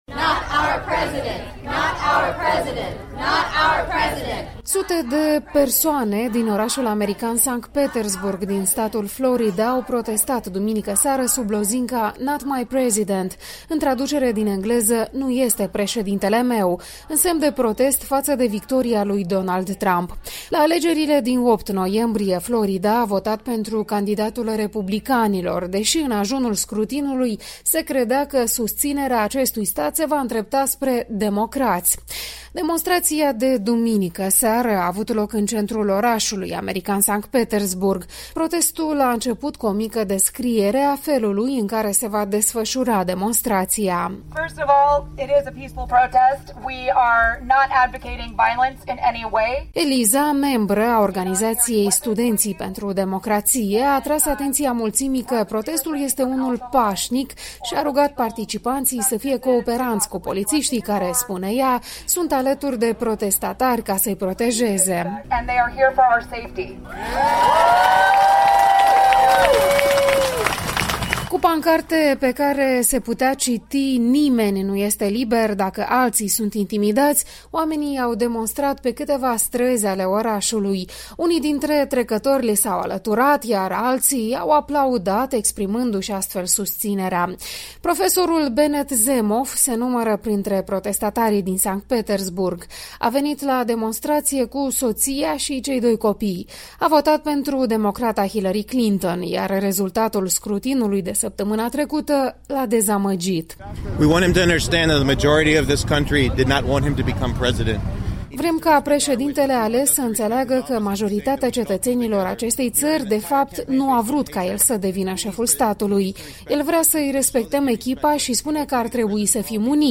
Un reportaj de la protestele americane anti-Trump de la St. Petersburg, Florida.